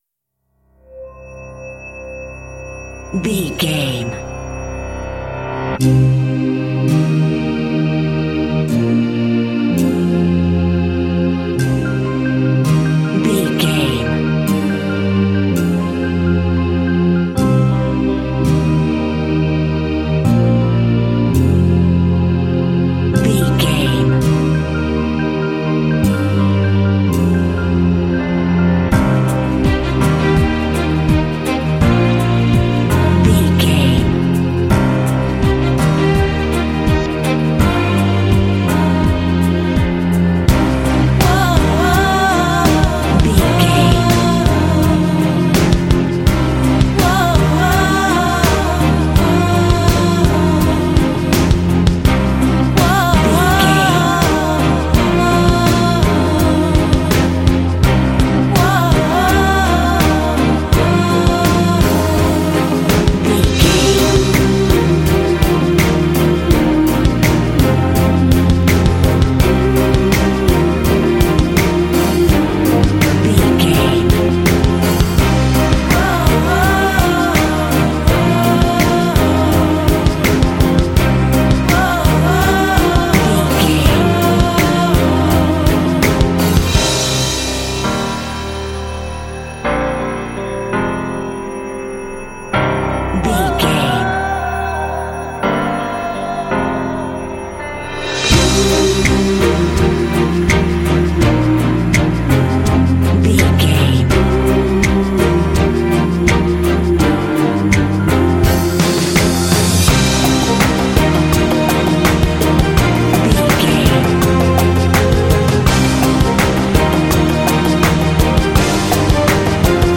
Epic / Action
Aeolian/Minor
powerful
inspirational
synthesiser
electric guitar
piano
strings
vocals
drums
bass guitar
cinematic
classical crossover